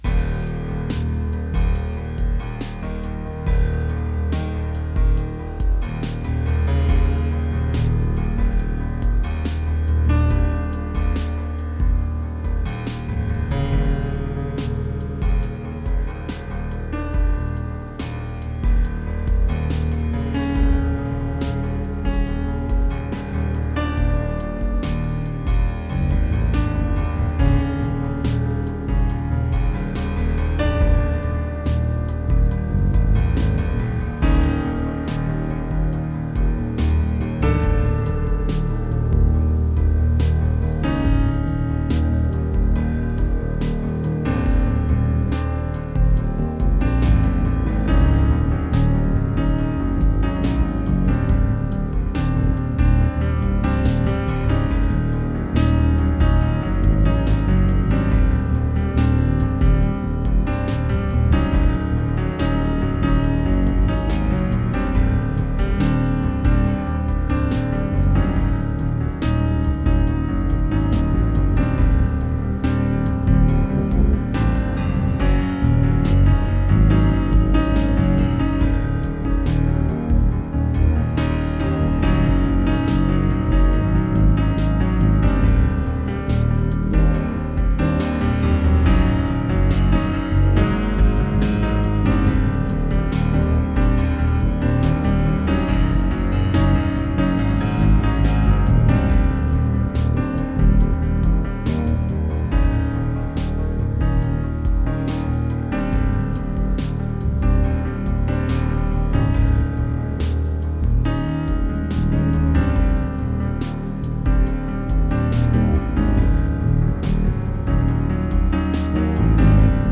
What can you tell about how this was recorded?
Mostly done with the M1 and SC-88.